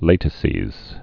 (lātĭ-sēz, lătĭ-)